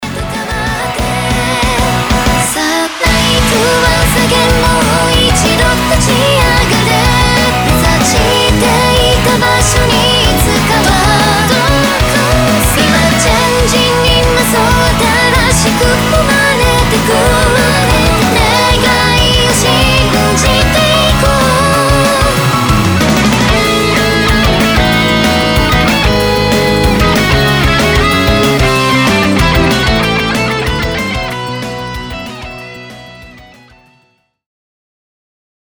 ROCK J-POP